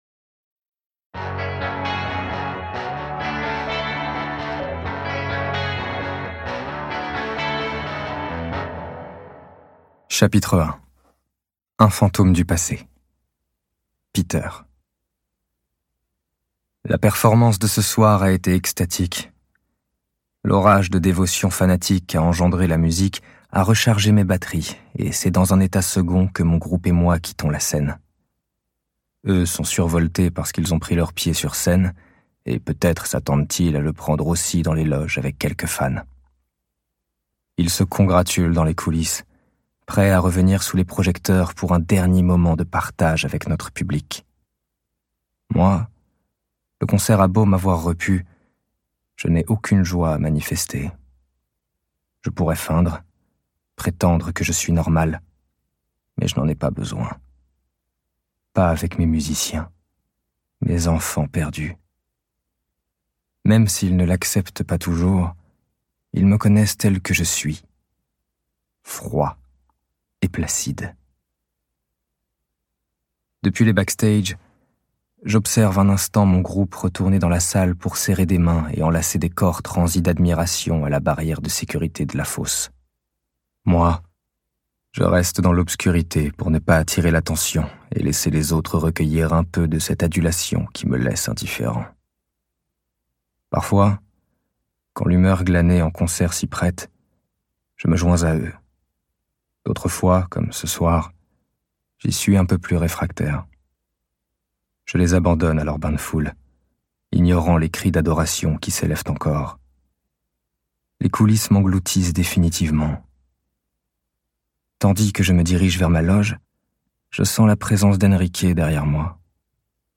Ce livre audio est interprété par une voix humaine, dans le respect des engagements d'Hardigan.